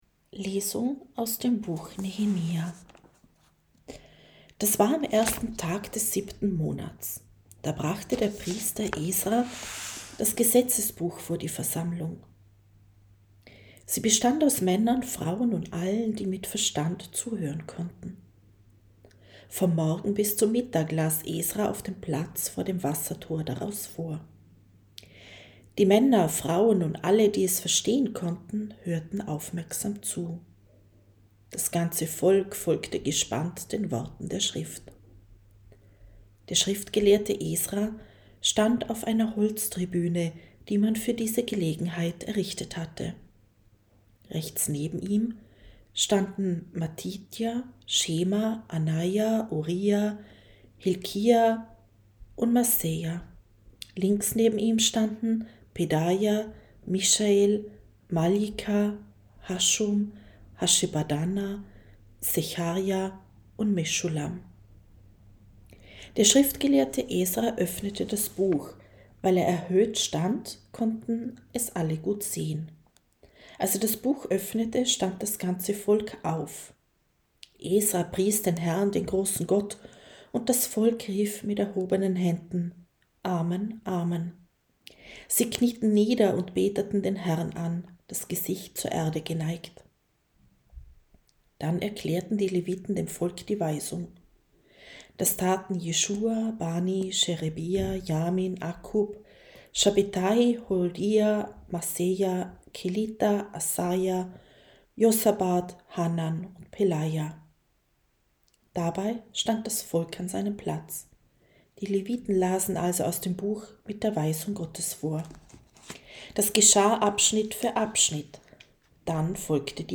1.-Lesung.mp3